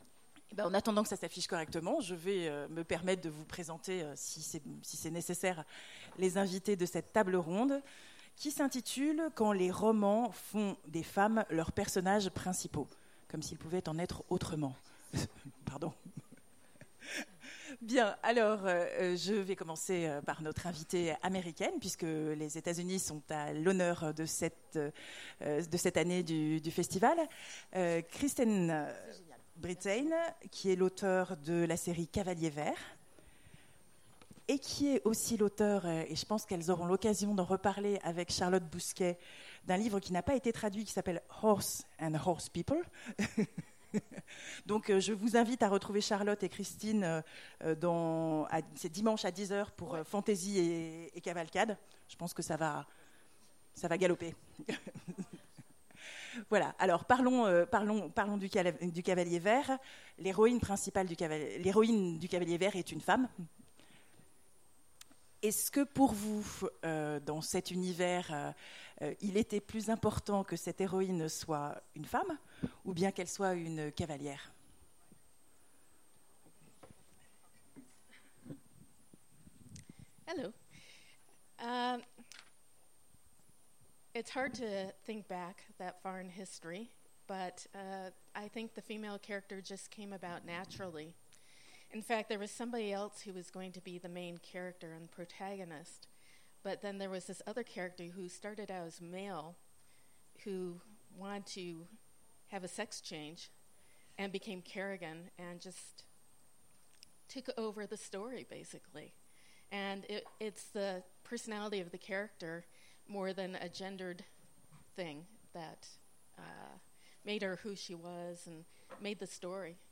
Imaginales 2016 : Conférence Quand les romans font des femmes…